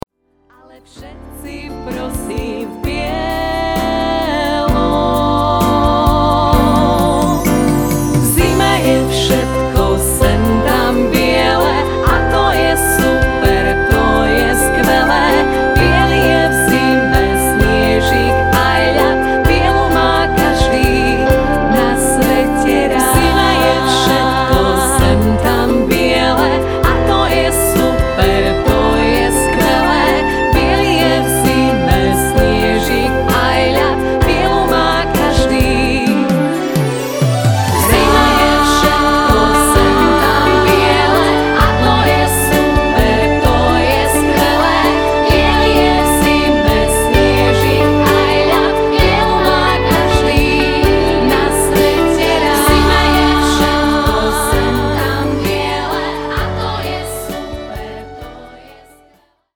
klarinet,saxofon,altová flauta
gitara
detský spev
deti zo ZUŠ v Malackách – zbor